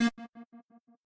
synth1_6.ogg